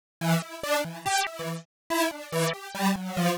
Index of /musicradar/uk-garage-samples/142bpm Lines n Loops/Synths
GA_SacherPad142E-02.wav